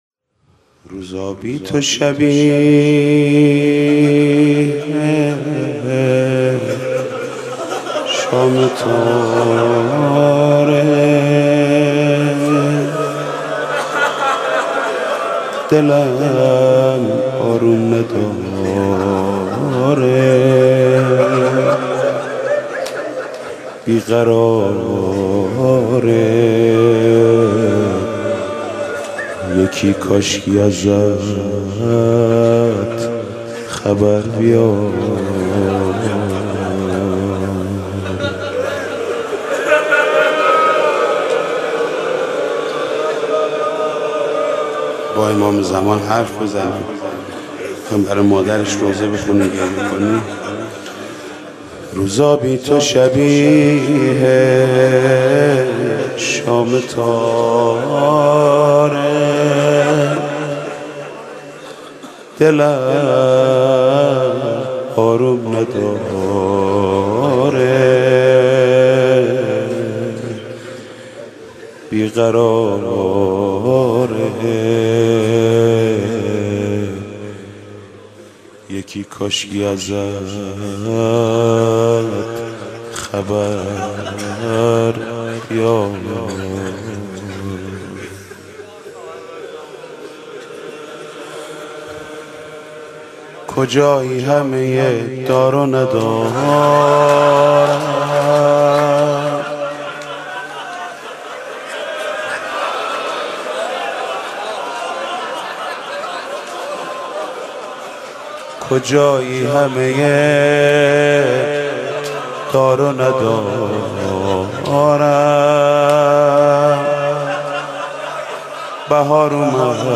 «فاطمیه 1391» مناجات: روزا بی تو شبیه شام تاره